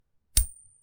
Coin Flip - 2
bet buy coin coins ding drop dropping falling sound effect free sound royalty free Sound Effects